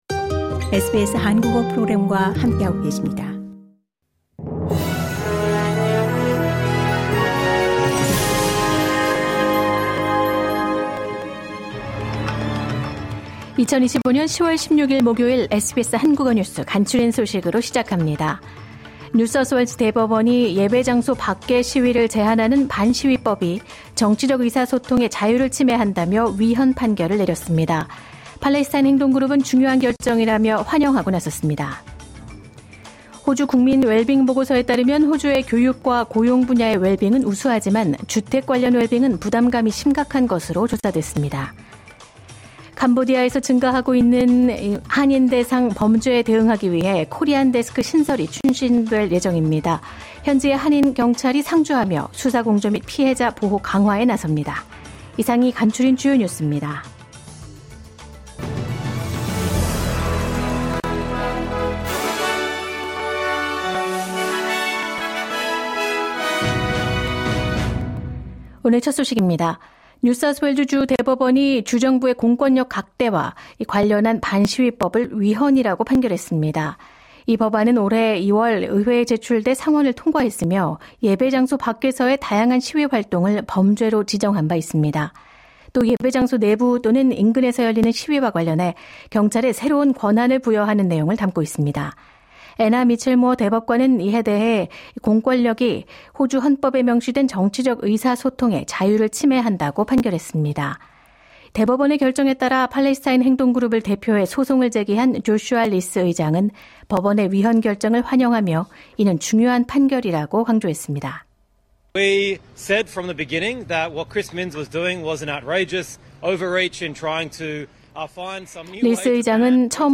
매일 10분 내로 정리하는 호주 뉴스: 10월 16일 목요일
주요 뉴스에서 환율, 내일의 날씨까지. 매일 10분 내로 호주에서 알아야 할 뉴스를 한국어로 정리해 드립니다.